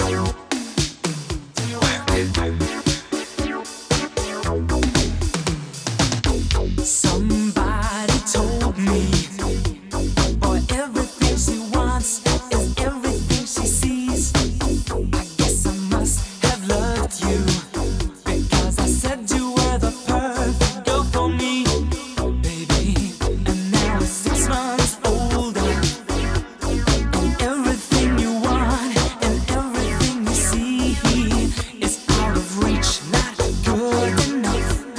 Жанр: Танцевальные / Поп / Рок / Альтернатива